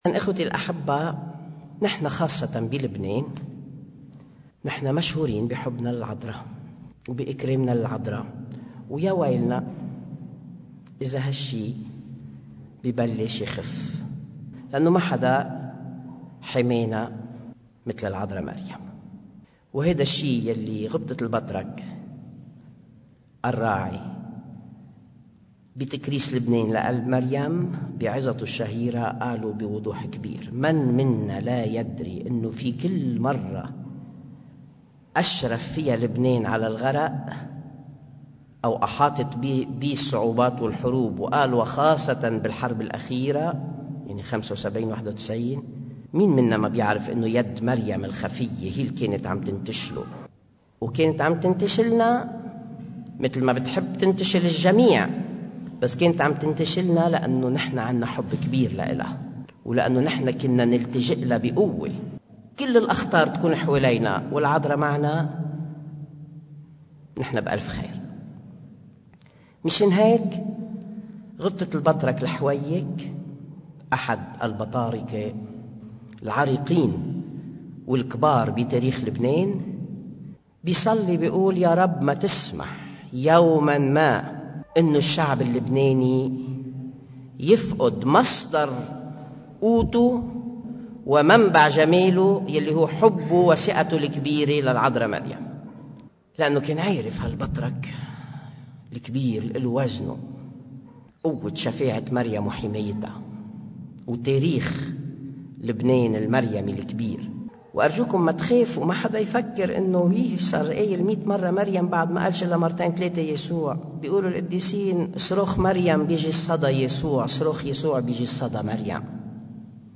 عظة
الرياضة الروحية الشهرية – نيسان ٢٠١٨ – دير قلب مريم – كنيسة القديسة فيرونيكا – القصيبة: